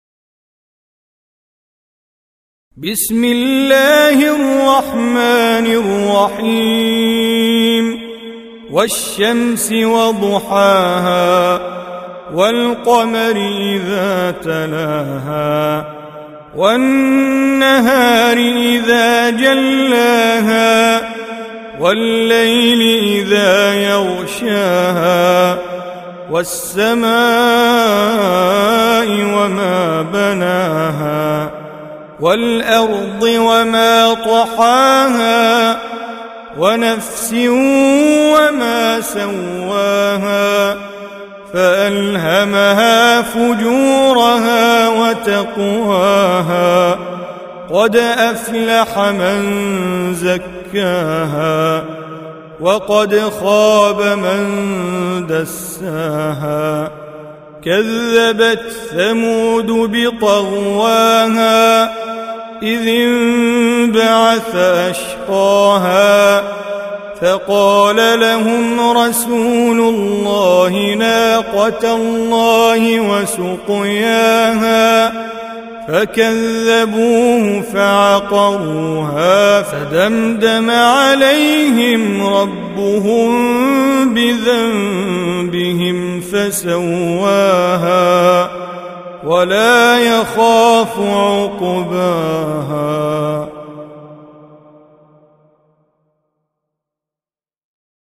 Surah Repeating تكرار السورة Download Surah حمّل السورة Reciting Mujawwadah Audio for 91.